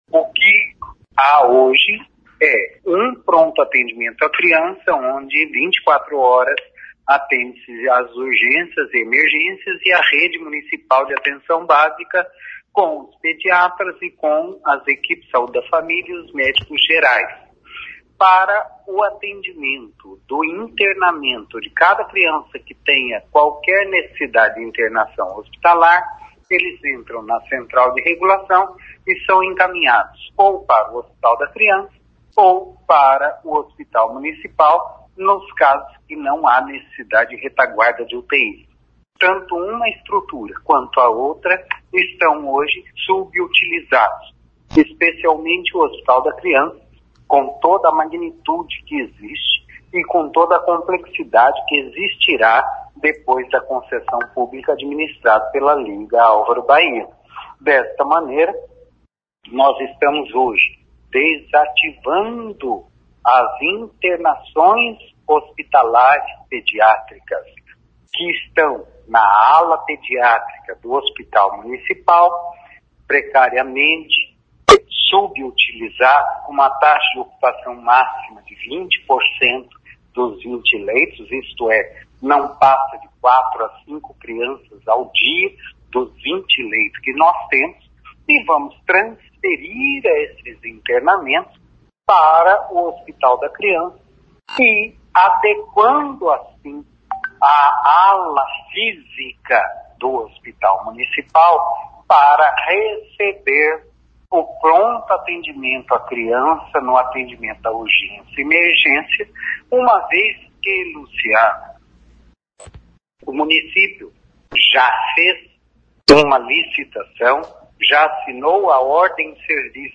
O secretário Antônio Carlos Nardi explica como fica o atendimento às crianças na rede municipal de saúde.